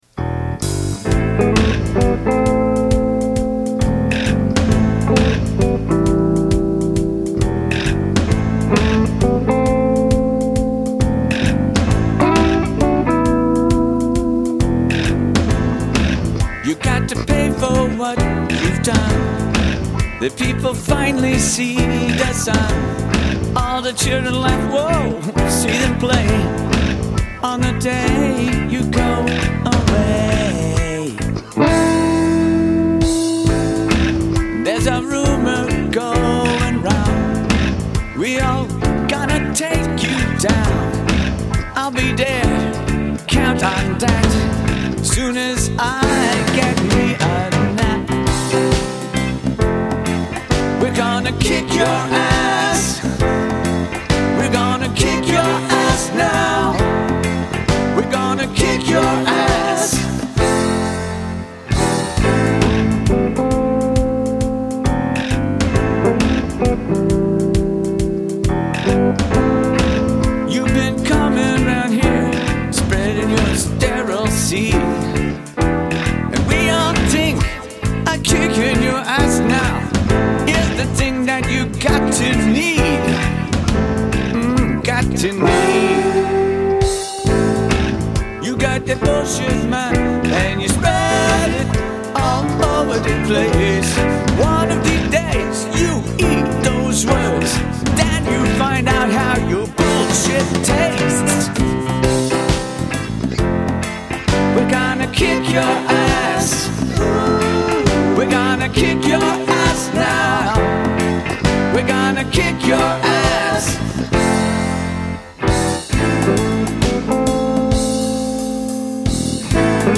I played all the instruments, and programmed a couple
This one was recorded here at home on
half track. Everything is running at 15ips.